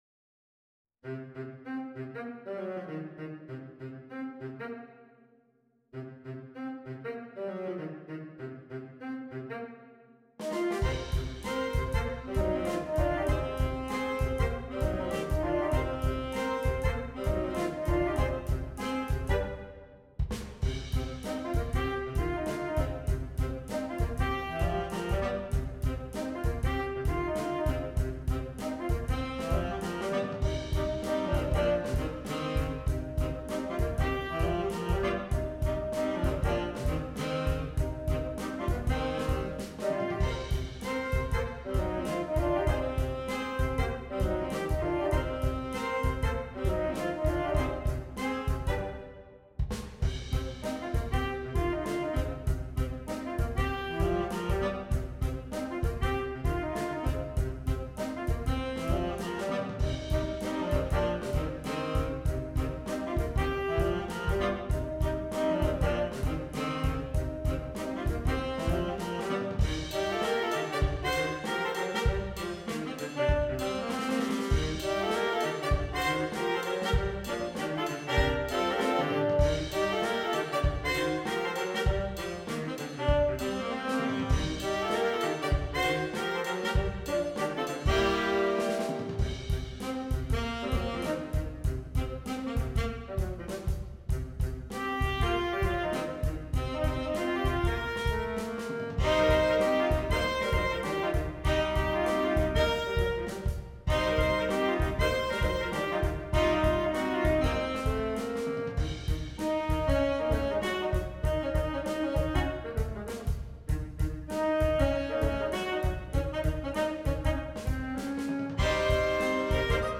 Saxophone Quartet (AATB)